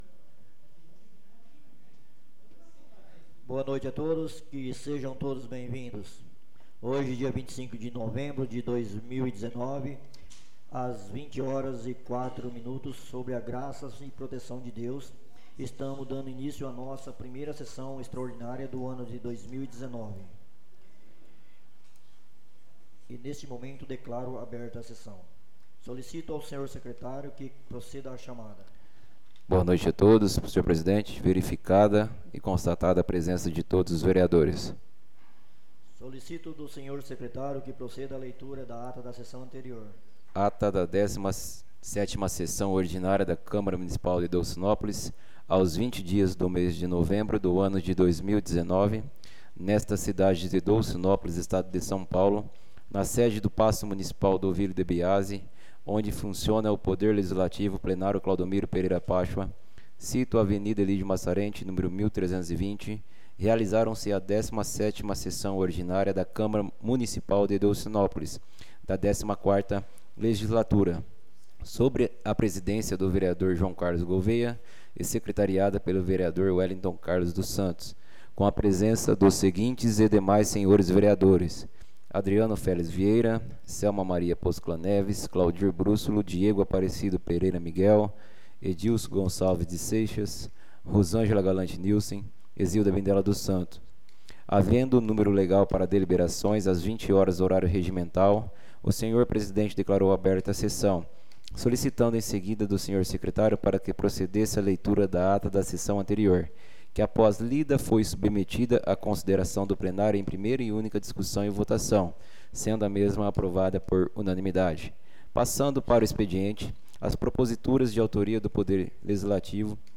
Áudio Sessão Extraordinária – 02/12/2019